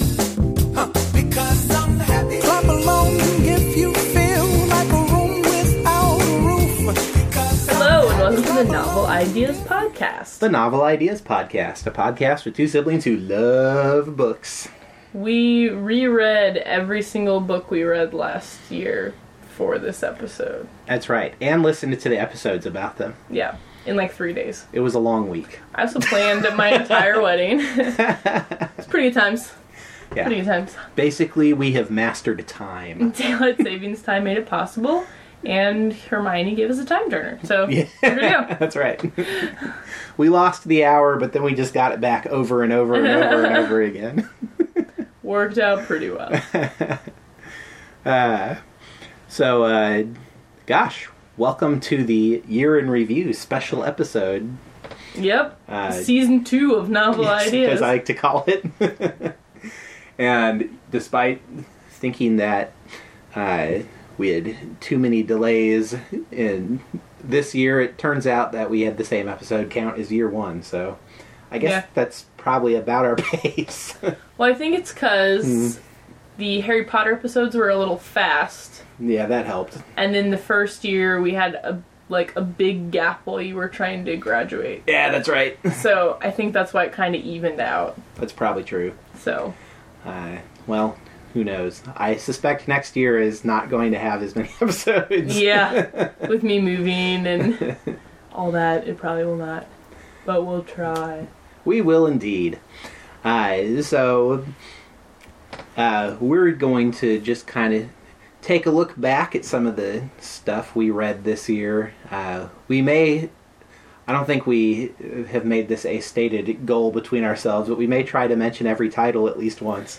For those of you playing at home, see if you can guess which twenty minutes were punched in after the fact.